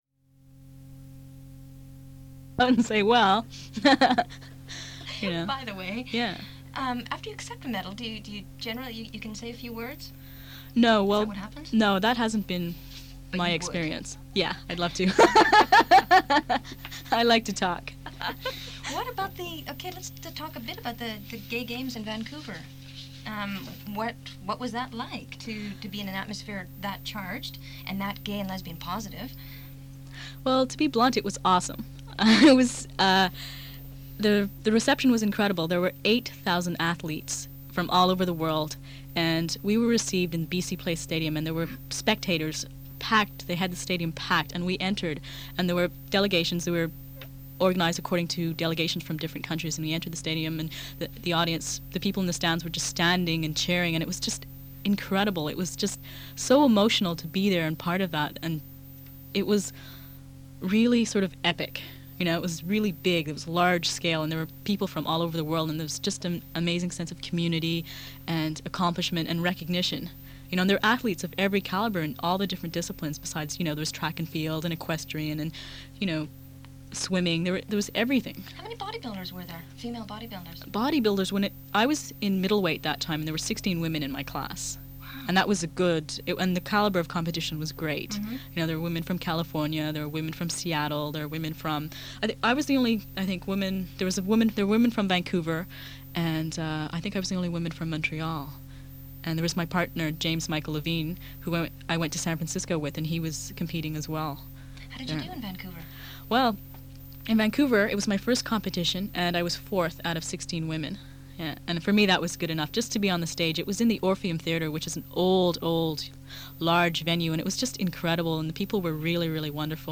The Dykes on Mykes radio show was established in 1987.